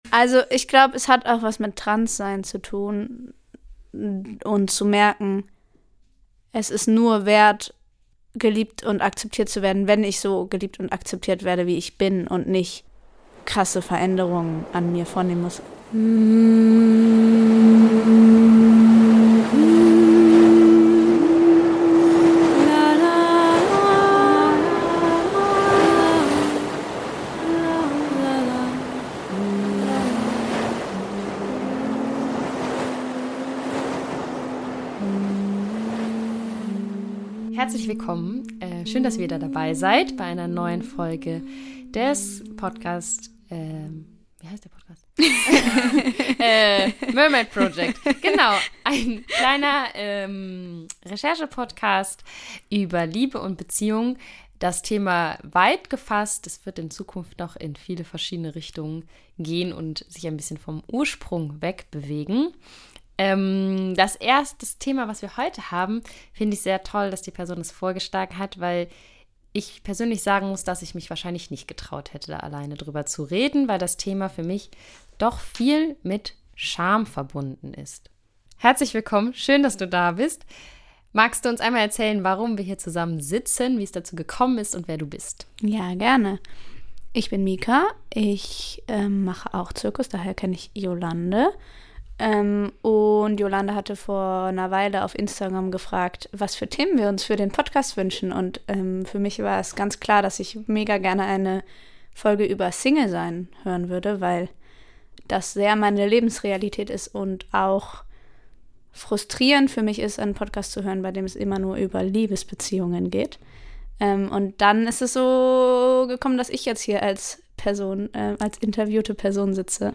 "Weniger weniger wert" - ein Gespräch über Singlesein und Scham ~ the mermaid project Podcast